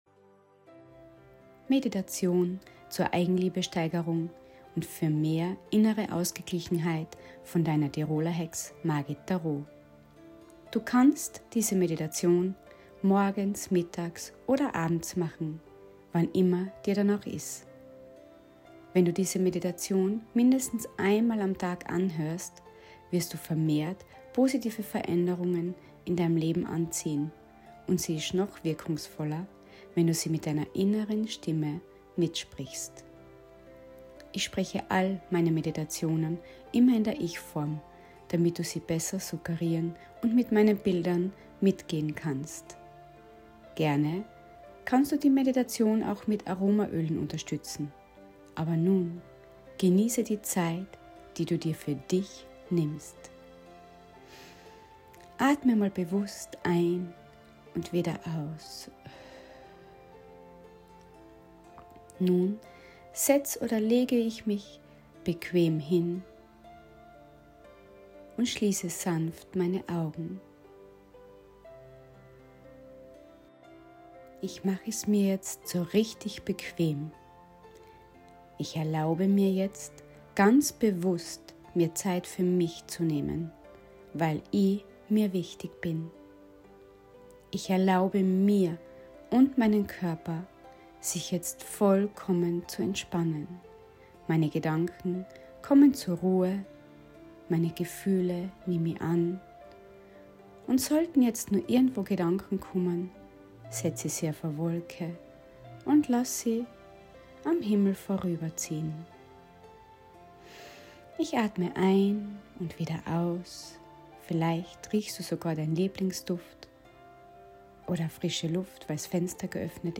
Eigenliebe-Meditation